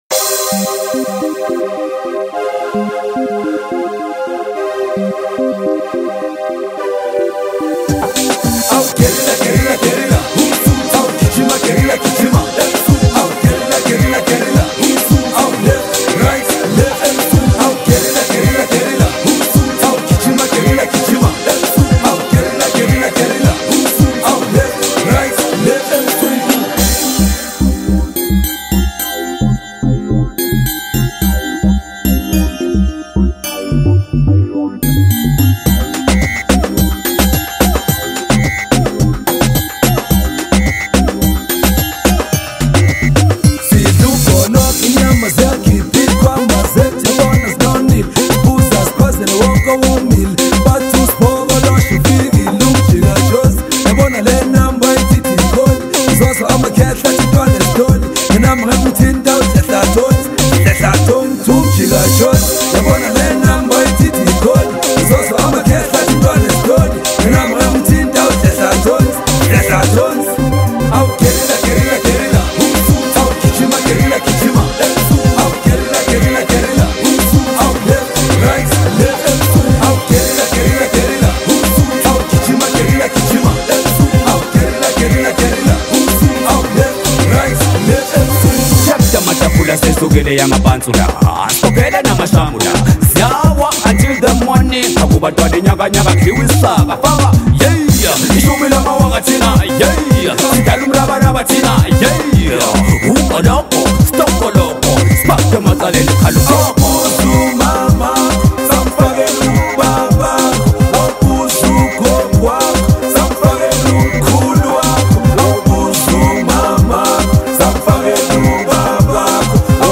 Nigerian singer
With its infectious beat and captivating vocals